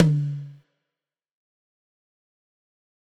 Tom_F2.wav